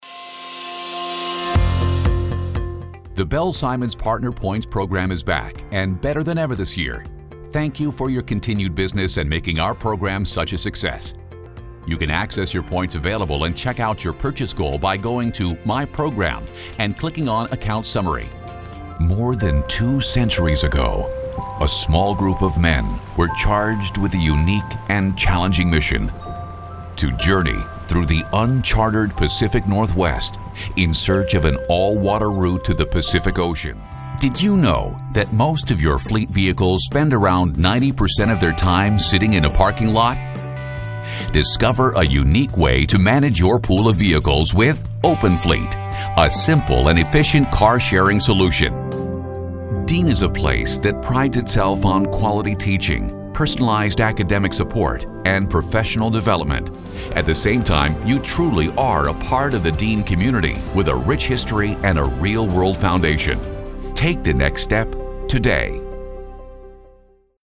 VOICE OVER DEMOS
Narration Voice Over